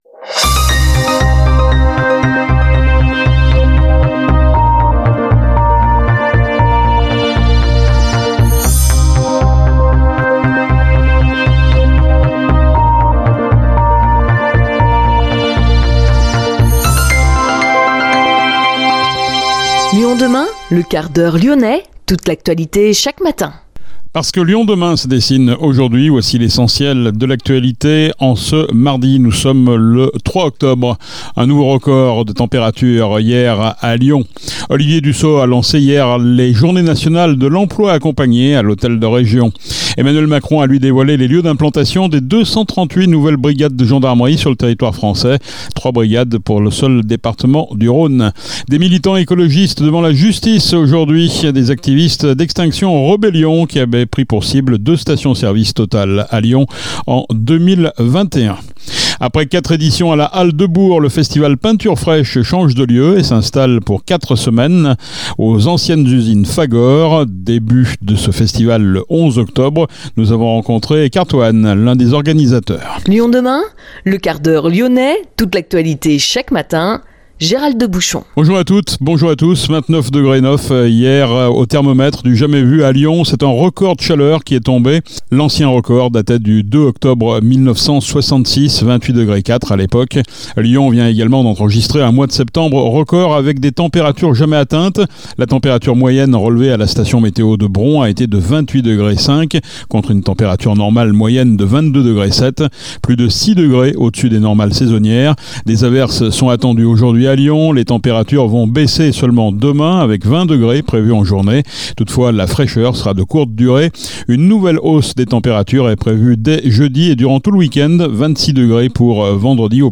Ecoutez l’interview Peinture Fraiche, du mercredi 11 octobre au dimanche 5 novembre 2023 aux usines Fagor.